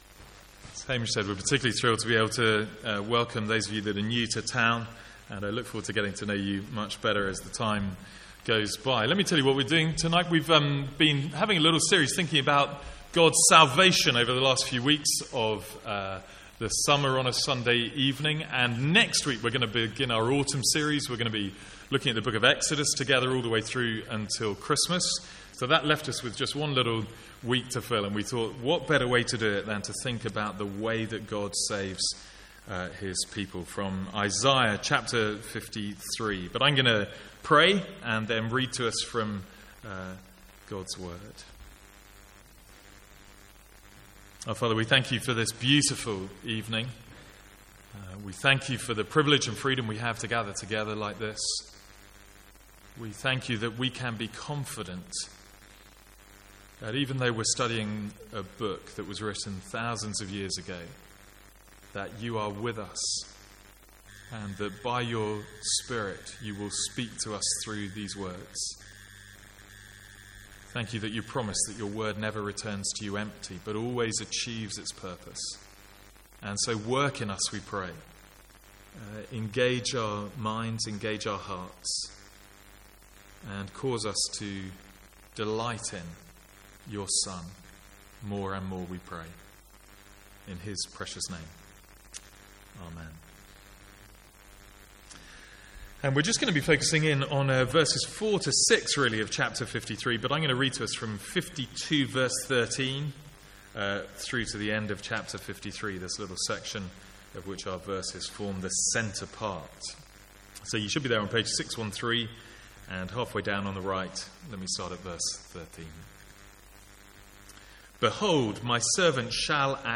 A one-off Sunday evening sermon on Isaiah 53.